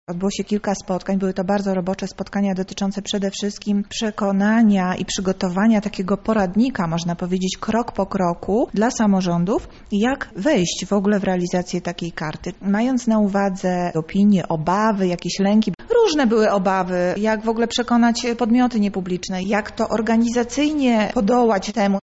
Jak na razie jesteśmy na początku drogi – zauważa Monika Lipińska, zastępca prezydenta Lublina do spraw społecznych.